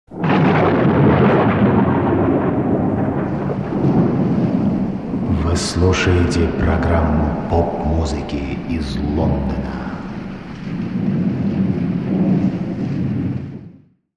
МузЗаставки